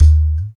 59 TALK DRUM.wav